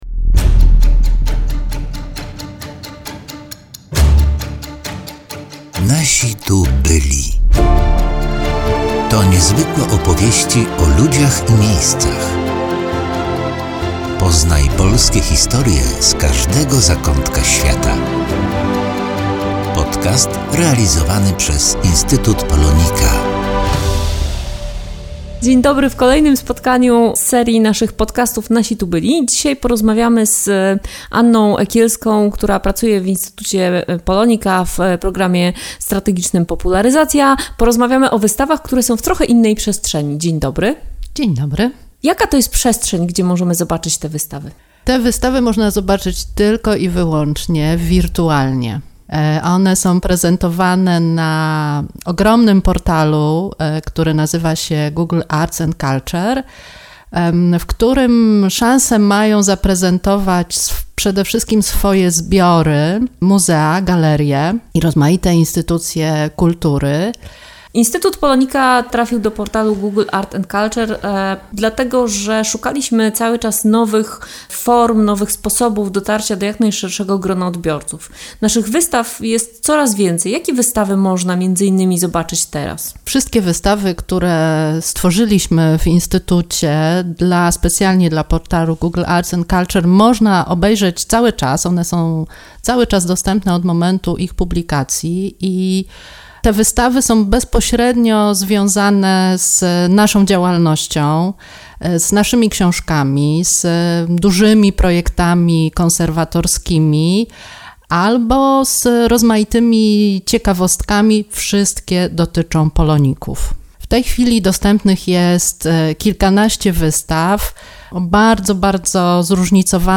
W szóstym odcinku rozmawiamy o wystawach Instytutu POLONIKA, które oglądać można na portalu Google Arts & Culture.